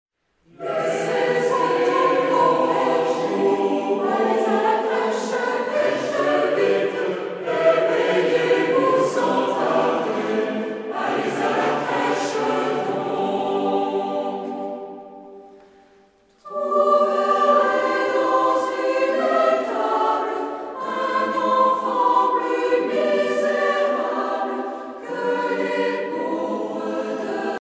Noël, Nativité
Genre strophique Artiste de l'album Psalette (Maîtrise)
Pièce musicale éditée